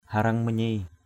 /ha-rʌŋ ma-ɲi:/ (d.) trời gầm = il tonne.